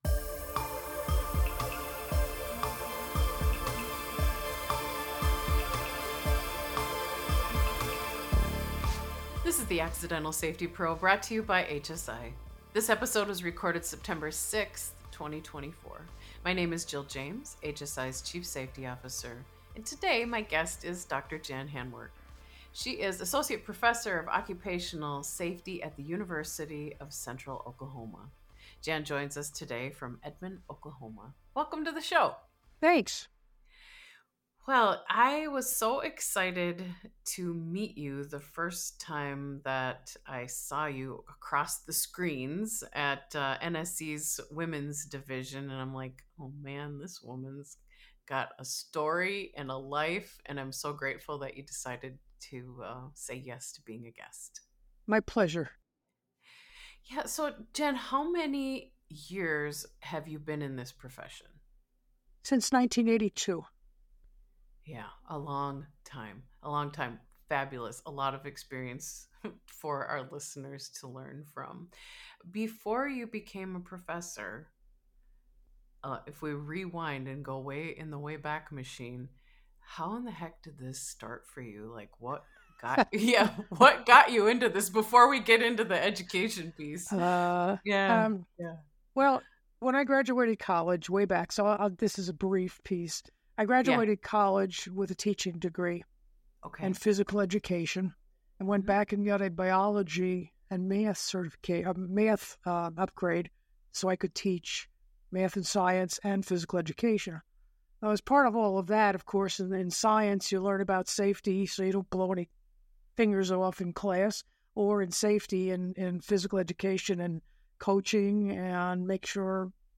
This safety podcast is a series of conversations with safety professionals about how they came into their role, what they've learned along the way, as well as some of the highs and lows that come with job.